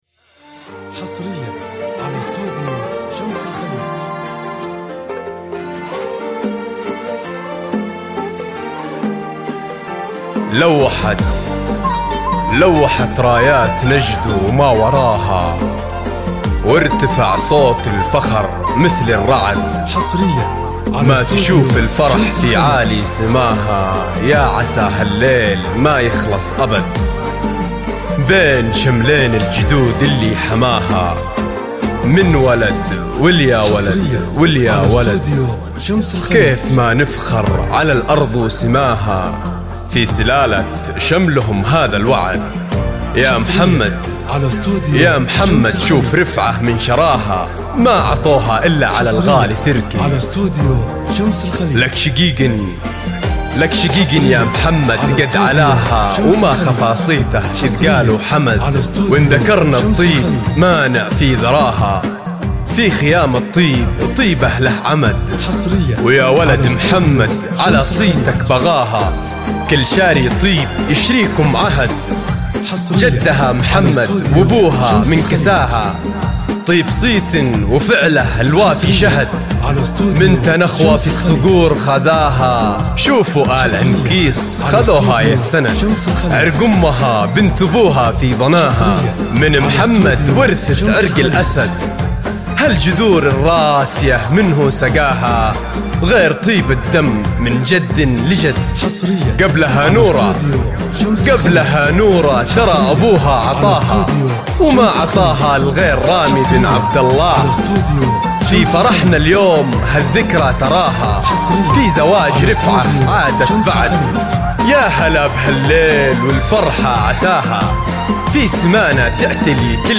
زفات موسيقى – أشعار